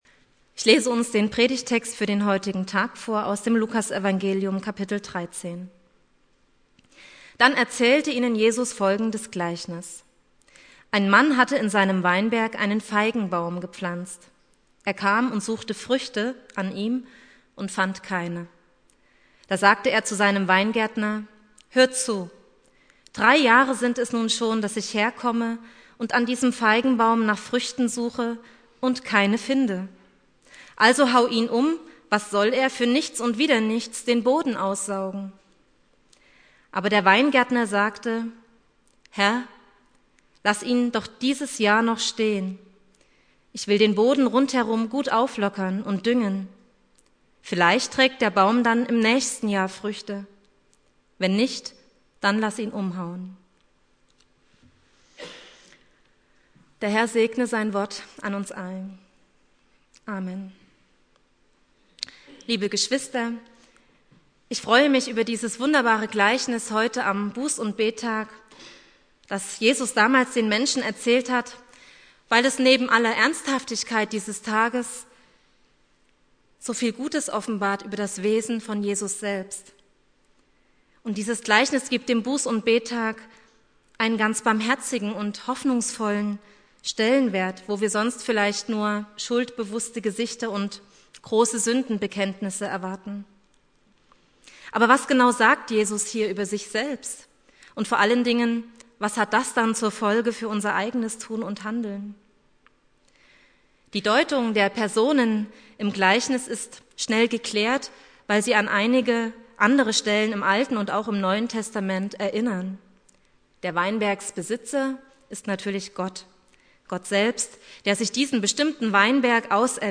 Predigt
Buß- und Bettag Prediger